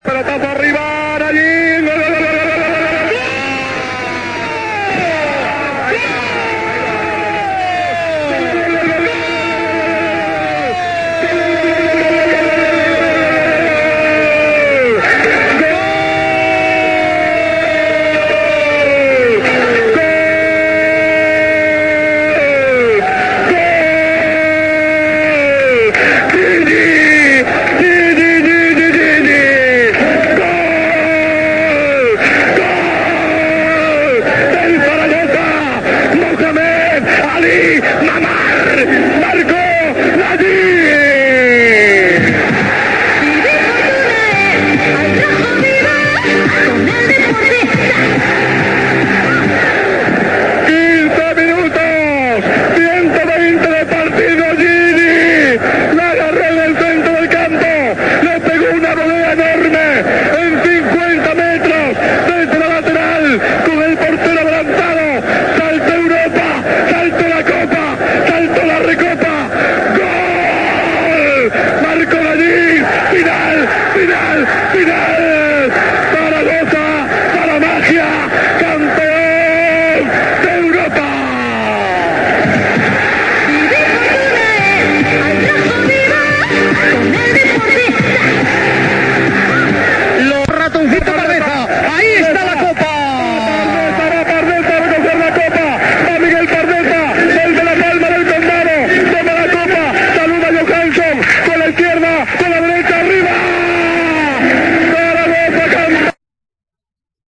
Así cantó Gaspar Rosety el histórico gol de Nayim para el Zaragoza
Una volea de Nayim en el último minuto de la prórroga dio la victoria en la Recopa de 1995 al Real Zaragoza ante el Arsenal. Gaspar Rosety lo cantó así.